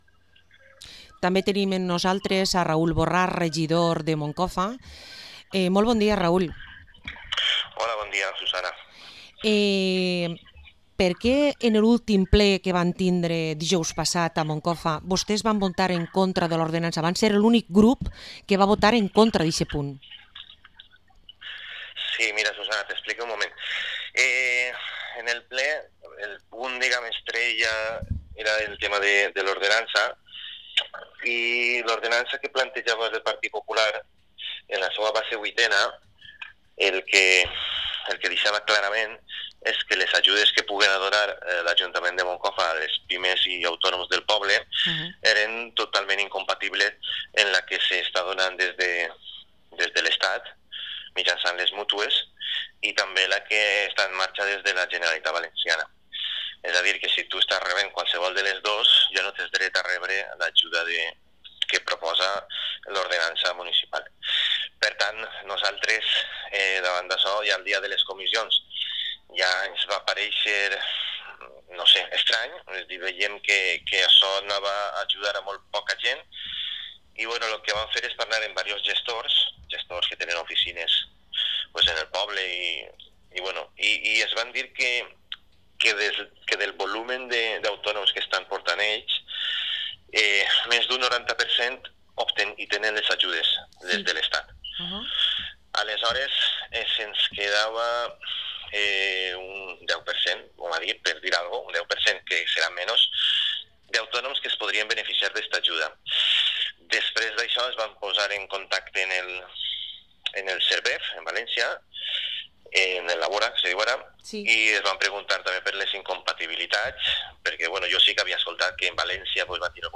Entrevista al concejal de Compromís per Moncofa, Raúl Borrás